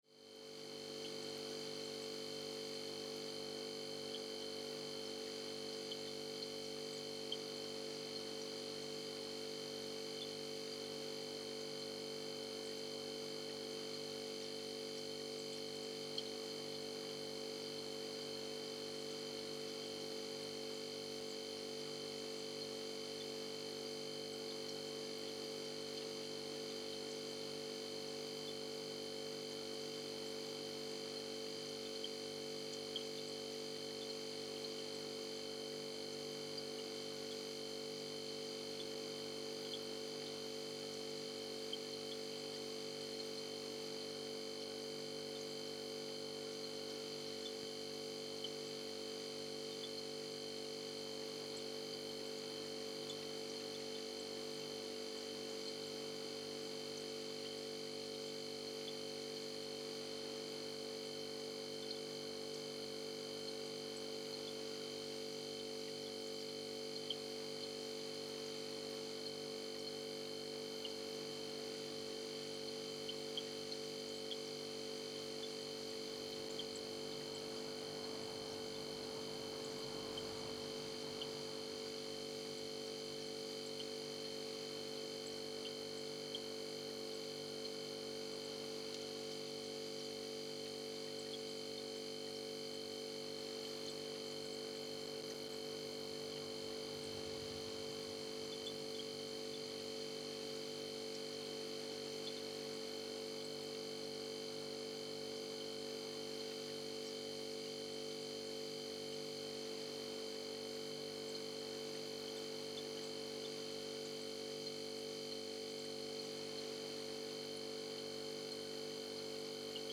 Скачать звуки холодильника и слушать
Работа разных холодильников, гул компрессора старого холодильника и звуки открытия и закрытия дверей.
Включение, шум работающего холодильника и выключение
holodilnik-20m.mp3